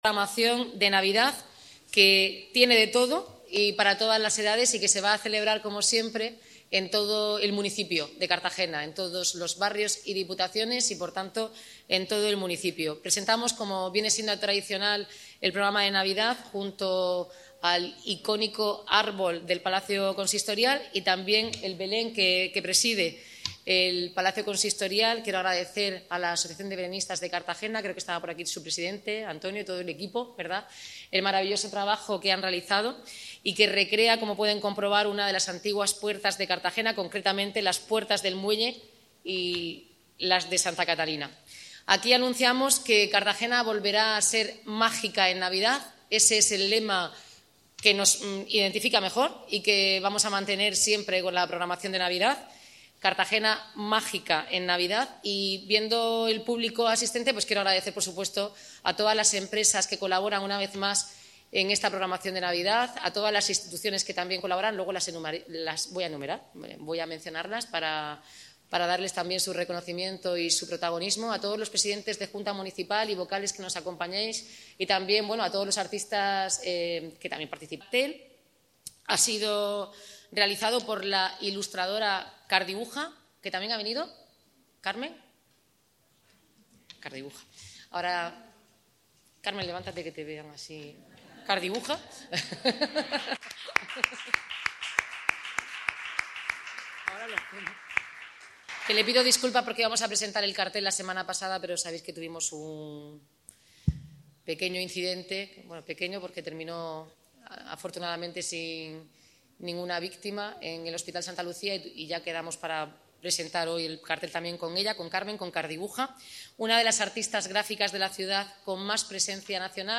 Audio: Declaraciones de la alcaldesa, Noelia Arroyo (MP3 - 7,60 MB)